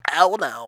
OH NO.wav